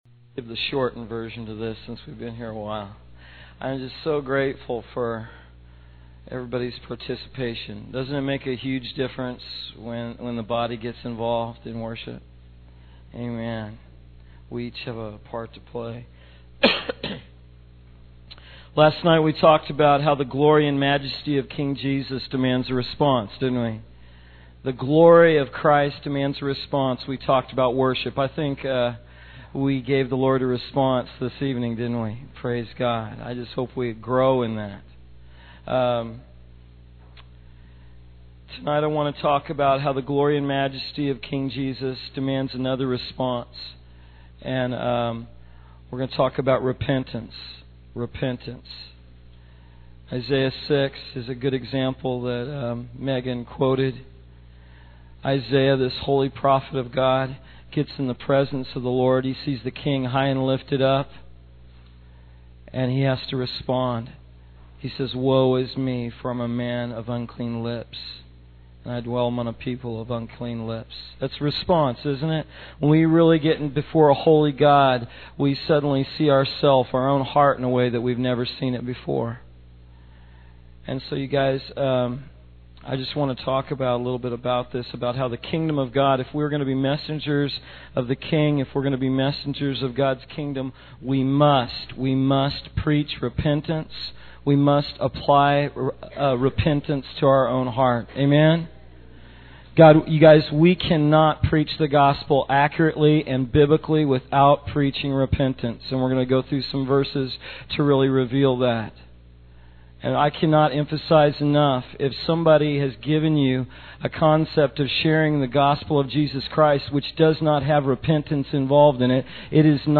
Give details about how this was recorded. High Quality